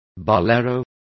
Complete with pronunciation of the translation of boleros.